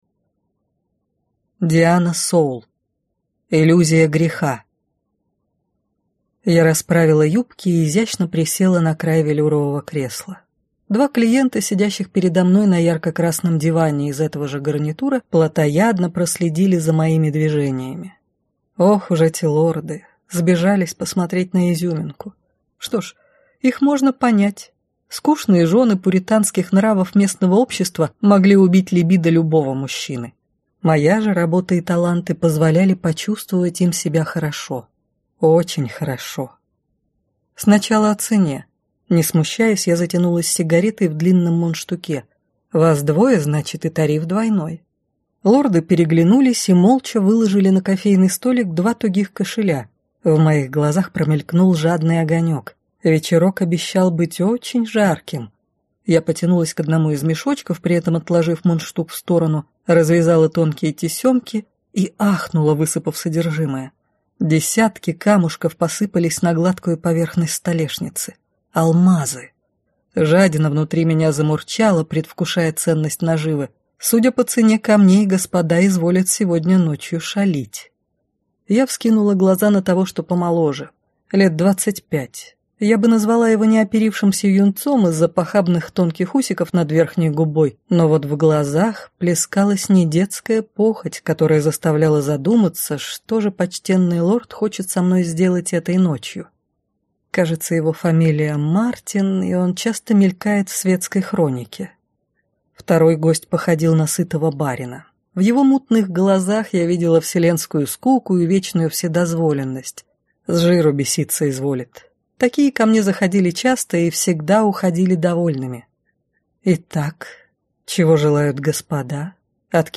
Аудиокнига Иллюзия греха - купить, скачать и слушать онлайн | КнигоПоиск